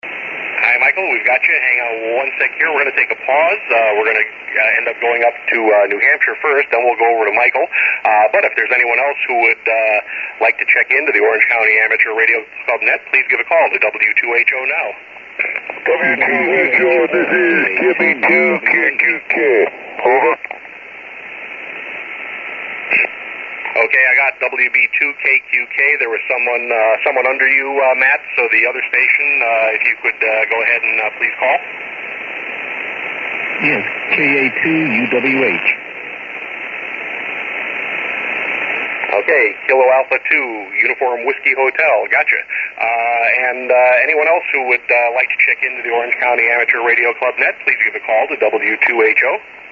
These are some audio samples of the Orange County Amateur Radio Club Net which meets Sundays at 12 noon on 3.920 MHz LSB.
Antenna: 75 meter dipole/Johnson Matchbox
Radio: ICOM IC745 transceiver/SB 200 amplifier
Signals are typically S5 to 10 over S9